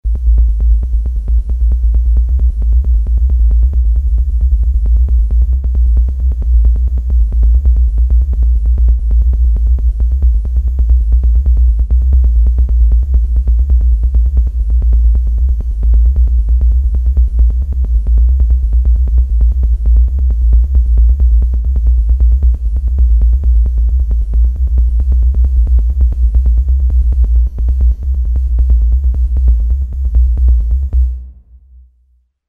bass drum line